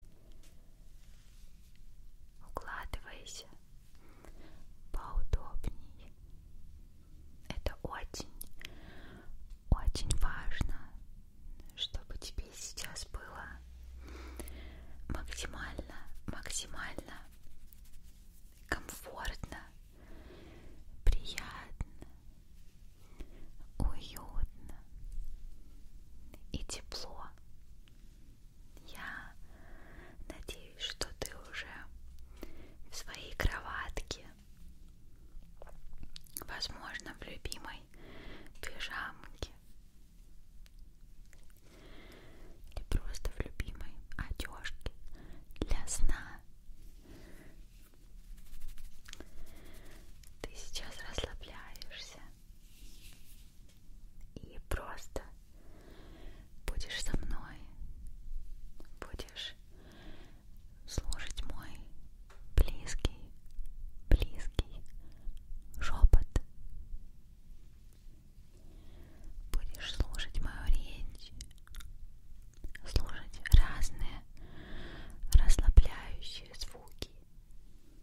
На этой странице собраны разнообразные звуки шепота: от загадочного женского до мужского нашептывания.
Шепот молодой девушки звук